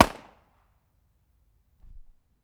Military Free sound effects and audio clips
• revolver 45 long colt shot.wav
revolver_45_long_colt_shot_exH.wav